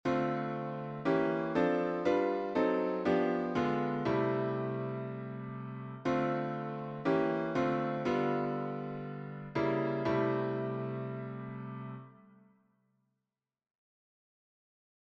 Improvisation Piano Jazz
Enchainement iim7-V7-I Majeur